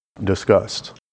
Can you differentiate the words discussed and disgust as produced by native speakers in natural sentences?
discussed or disgust? (USA)